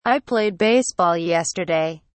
Conversation Dialog #1: